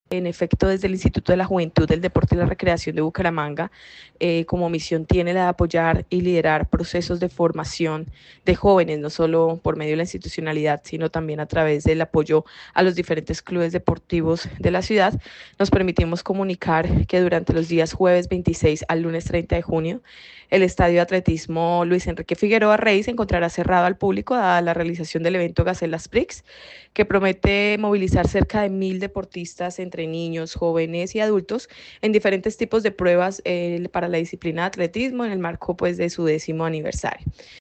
Sandra Rodríguez, directora del Inderbu